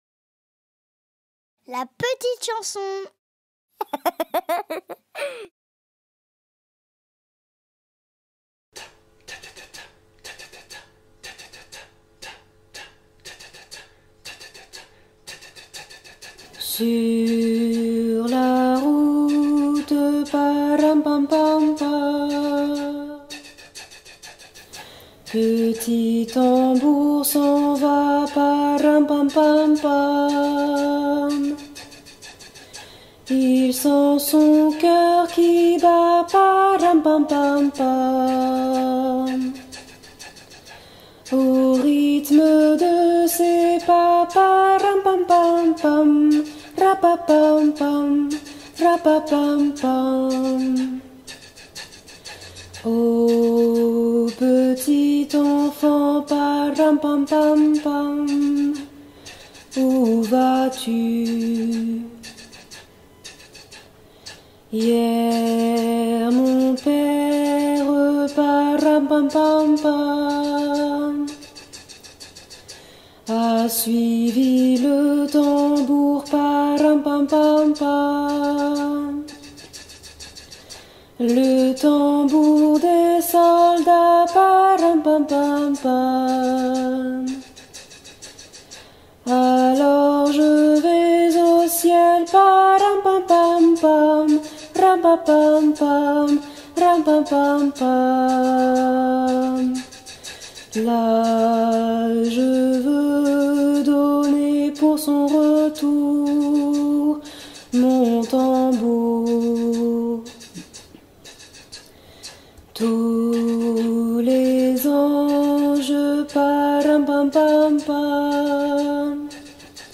MP3 versions chantées
A 3 Voix Mixtes Voix 2 Theme Alto